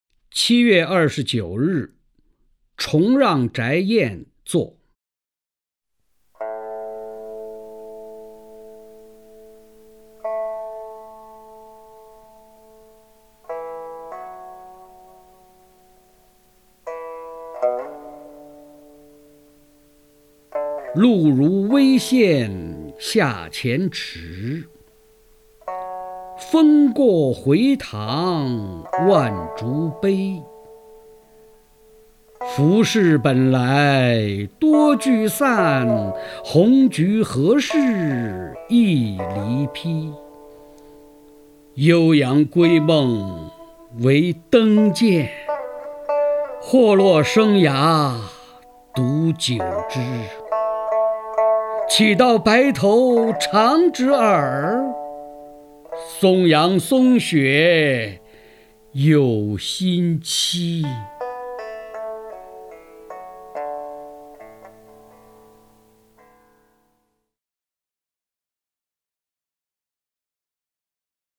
曹灿朗诵：《七月二十九日崇让宅宴作》(（唐）李商隐)
名家朗诵欣赏 曹灿 目录